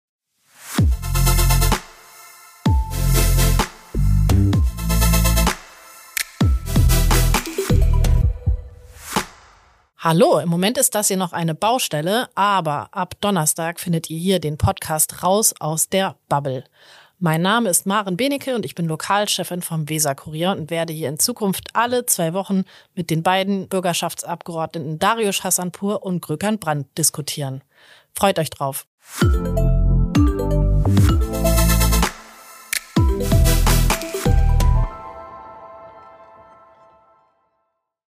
"Raus aus der Bubble": Die Bürgerschaftsabgeordneten Dariush Hassanpour (Linke) und Gökhan Brandt (FDP) diskutieren.